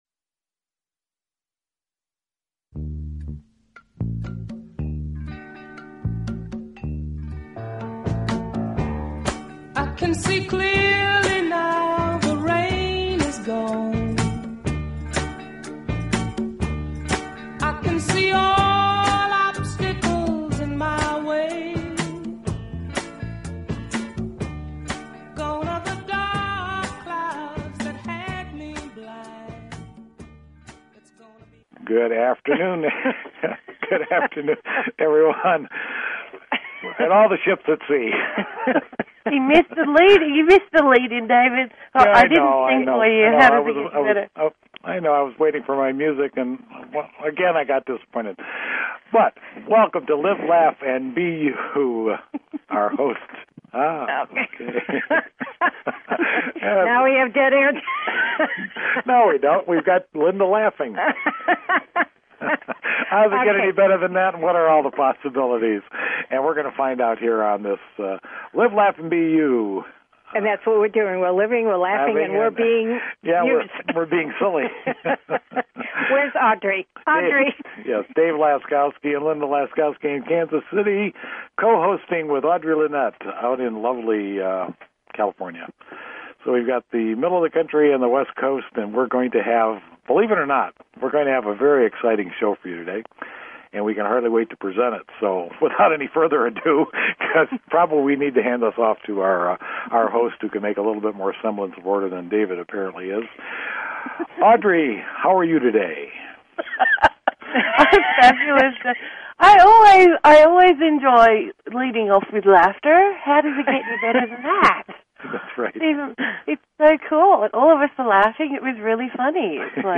Talk Show Episode, Audio Podcast, Live_Laugh_and_BE_You and Courtesy of BBS Radio on , show guests , about , categorized as